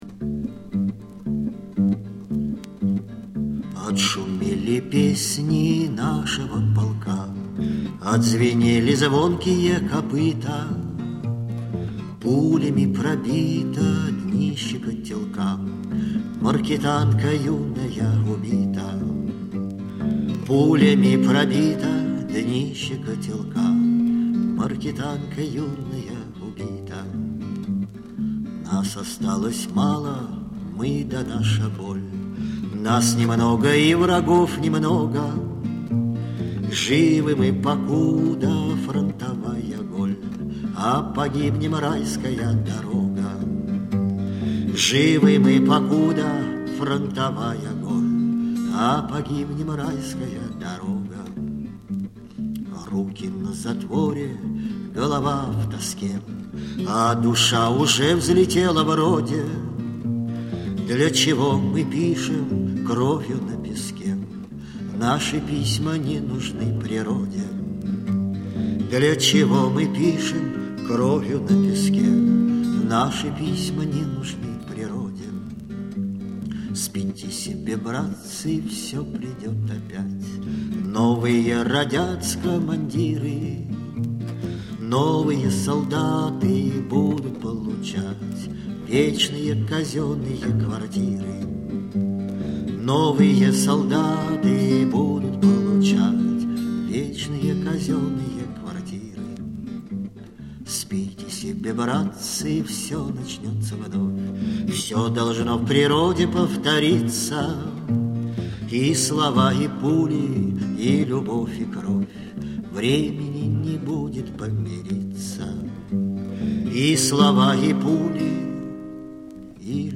Gm D7 Gm